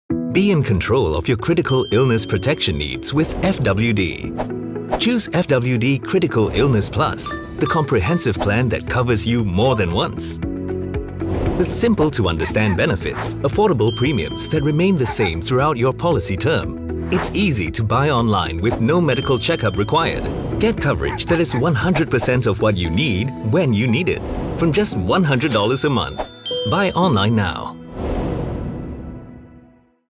English (Singapore)
Explainer Videos
Baritone
WarmAuthoritativeConversationalFriendlyDarkEngagingAssuredReliable